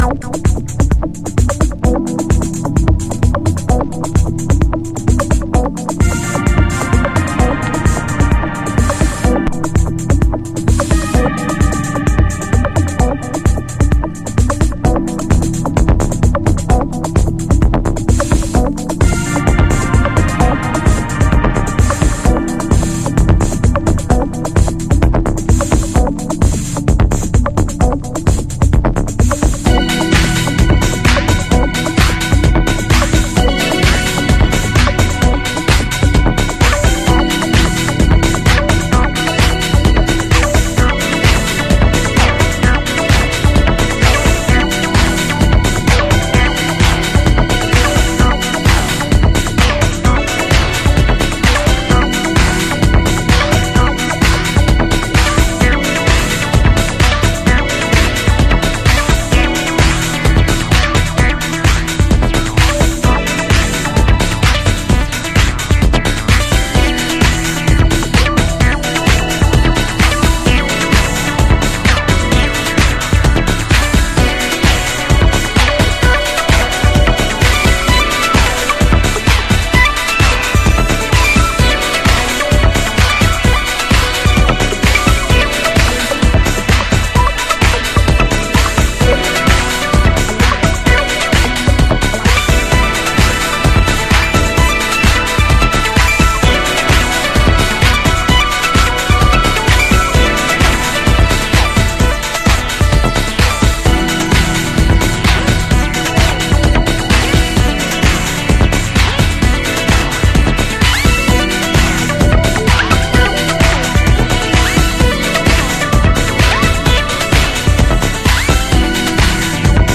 House / Techno
ピュアなハートが痺れるめくるめくマシンドリームテクノ。